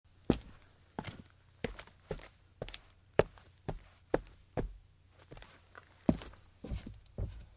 Download Footsteps sound effect for free.
Footsteps